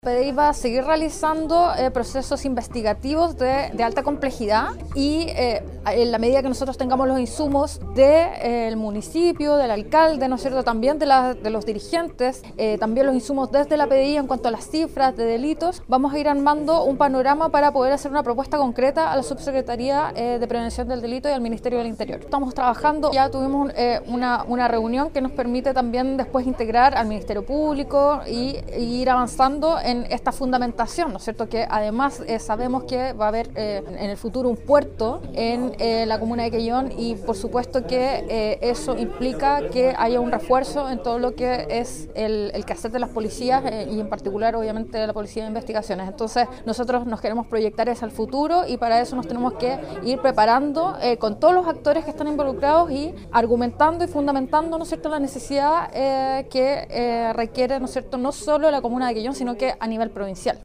En tanto, la Delegada Presidencial, Mariela Núñez, dijo que la PDI, seguirá realizando investigaciones de alta complejidad y ya trabajan en conjunto con todos los actores para en el futuro contar con una unidad permanente en el puerto sur de la provincia: